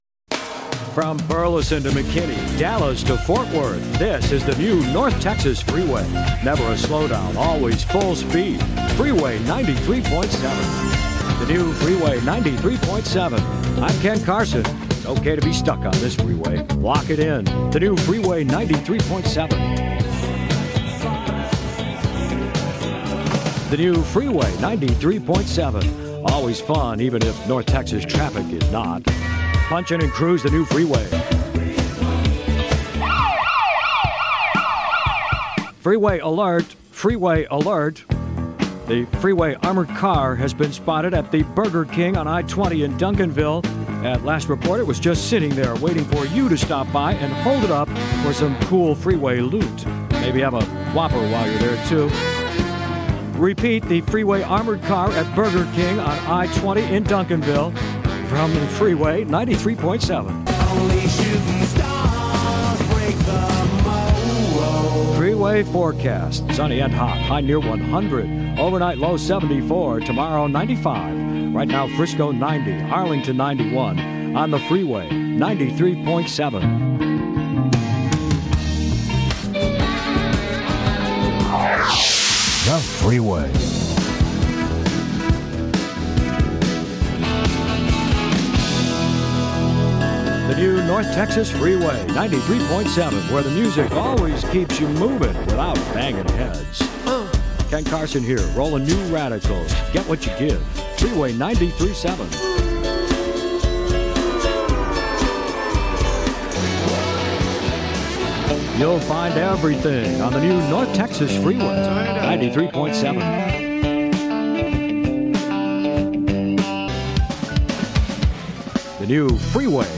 Radio Aircheck
English - Midwestern U.S. English
Middle Aged